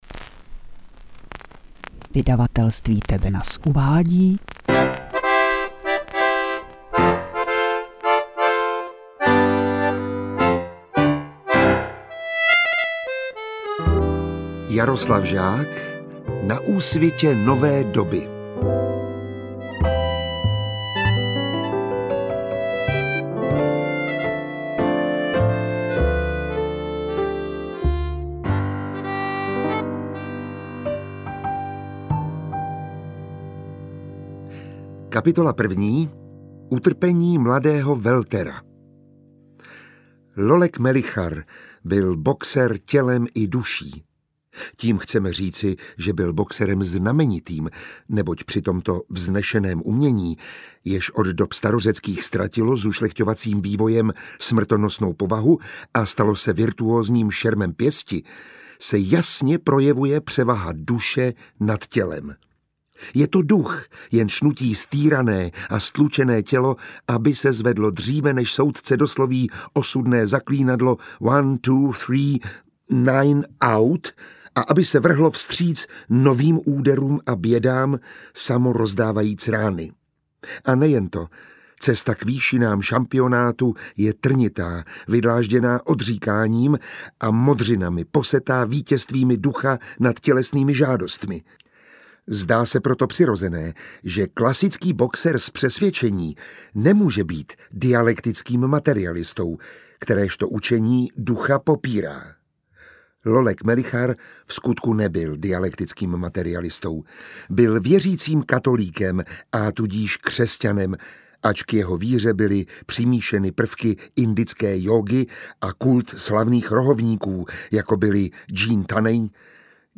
Interpret:  Miroslav Táborský
Český herec, laureát Ceny Alfréda Radoka za mužský herecký výkon roku v roli Jindřicha v inscenaci Konec masopustu a nositel španělské Goyovy ceny.
AudioKniha ke stažení, 26 x mp3, délka 11 hod. 37 min., velikost 818,6 MB, česky